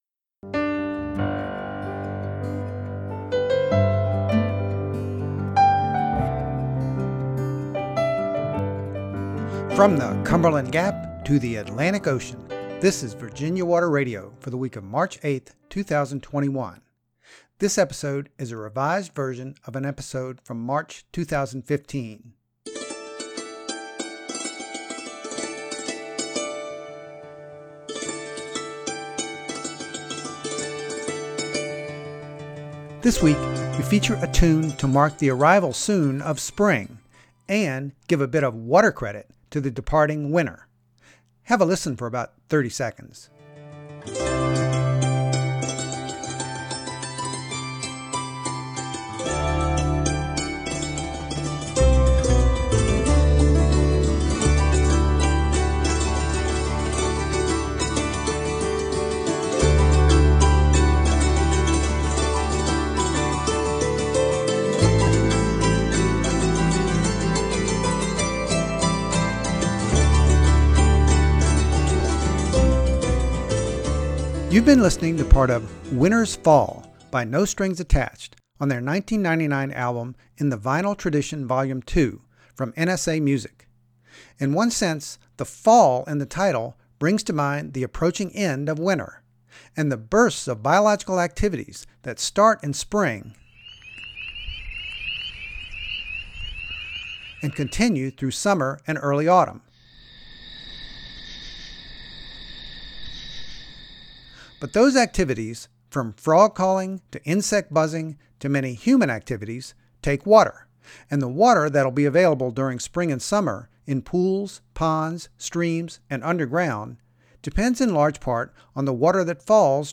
The sounds heard were Spring Peepers in Blacksburg, Va., March 11, 2015; and various night-time insect sounds in Blacksburg, October 2, 2014.